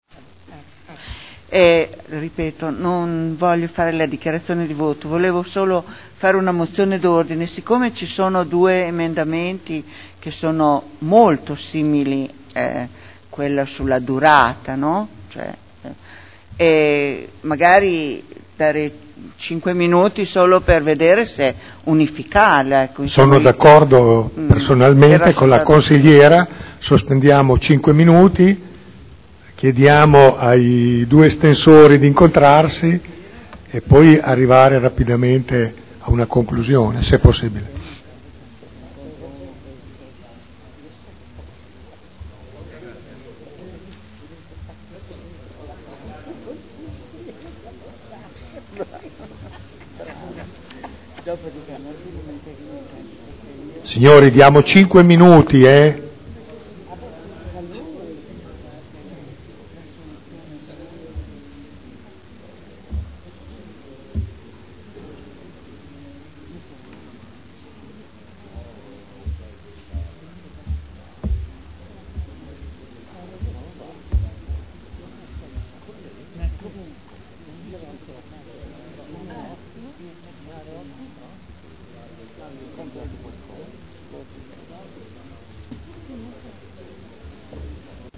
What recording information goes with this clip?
Seduta del 12/04/2012. Mozione d'ordine sugli emendamenti. Dibattito.